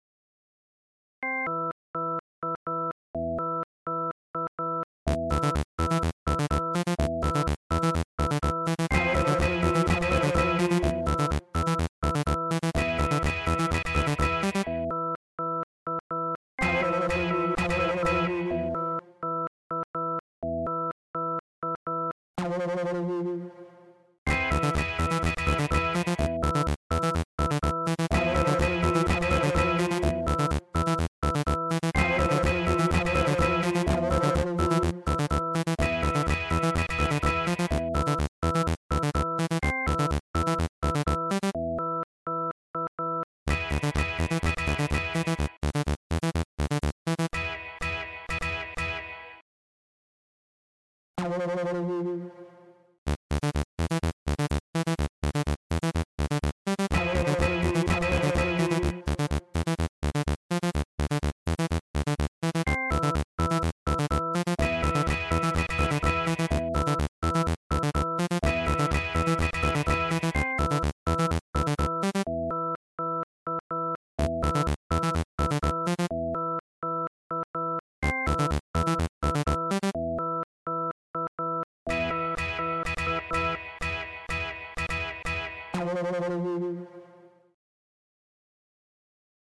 Minimal music(1.02mb)
This was an attempt at German style "minimalist" music.